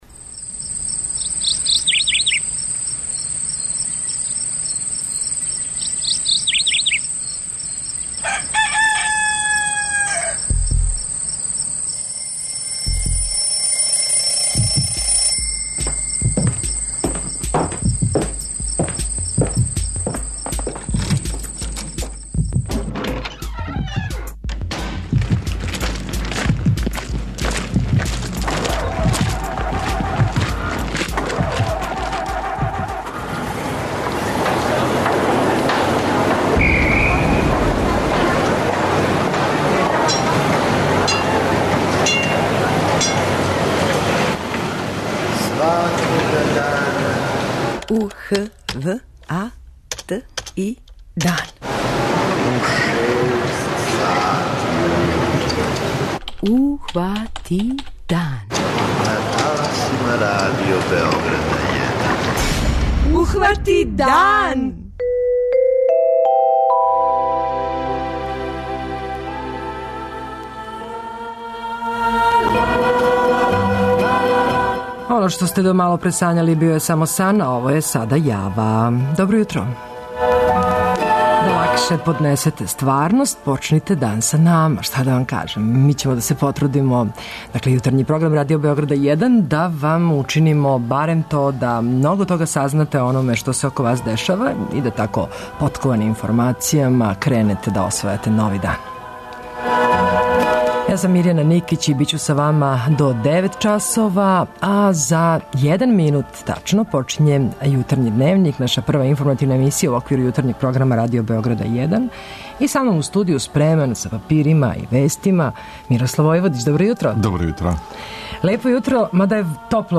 преузми : 85.90 MB Ухвати дан Autor: Група аутора Јутарњи програм Радио Београда 1!